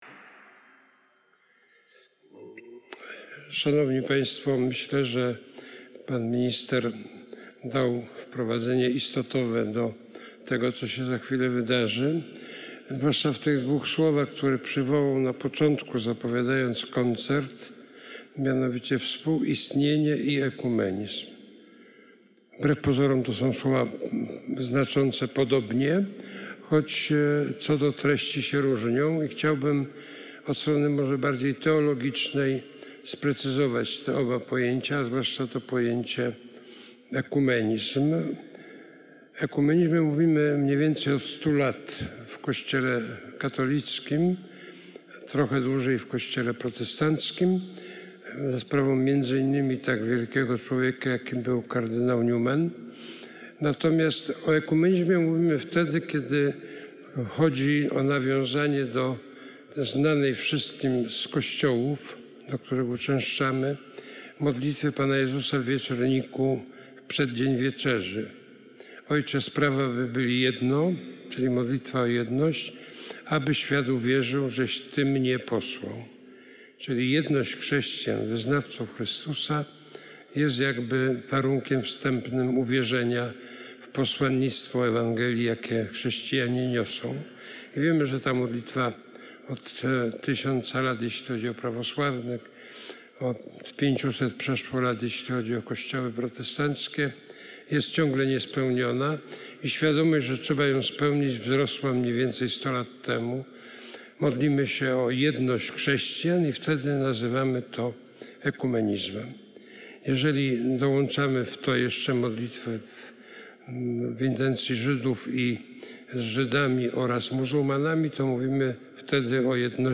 kardynał NyczArcybiskup metropolita warszawski w swoim słowie, wygłoszonym przed rozpoczęciem pierwszego koncertu precyzował pojęcia „ekumenizm” i „współistnienie” w kontekście Ewangelii i dzisiejszej sytuacji międzynarodowej. Wyraził nadzieję, że ten koncert zmobilizuje nas do refleksji i modlitwy.
PrzemowienieKardynalNycz.mp3